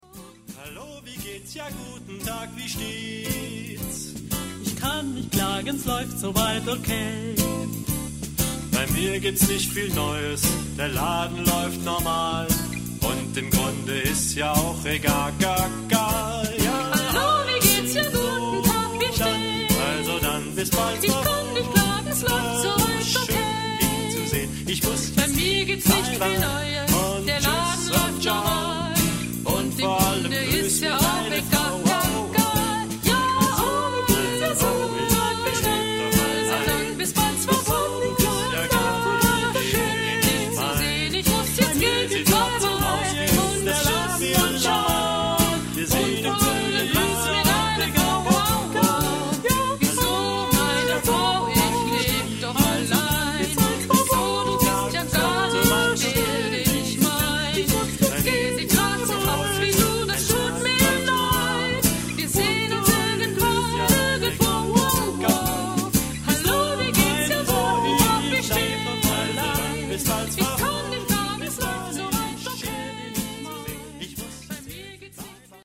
Género/Estilo/Forma: Canon ; Swing ; Profano
Tipo de formación coral:  (3 voces iguales )
Tonalidad : sol mayor